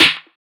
SNARE 18.wav